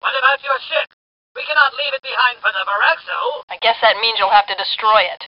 ―MK-09 and Aeron Azzameen discuss the fate of the stranded Selu — (audio)